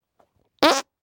Звуки подмышки